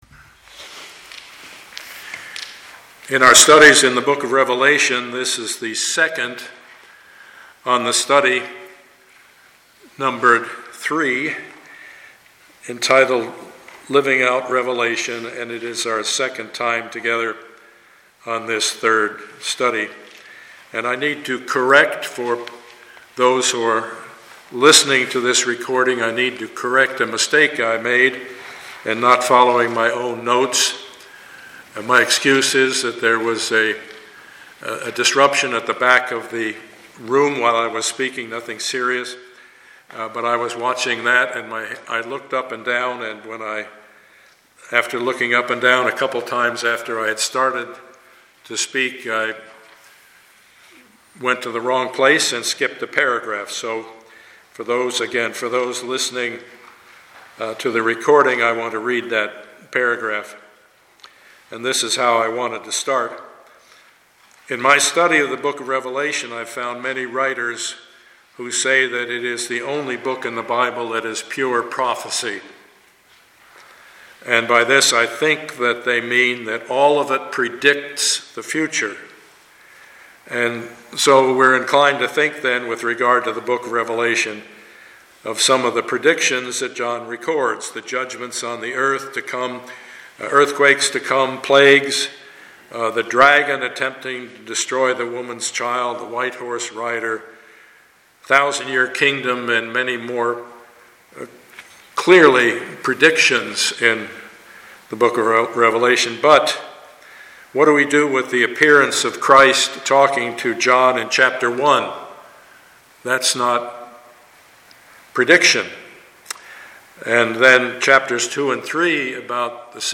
Passage: Revelation 1:1-8 Service Type: Sunday morning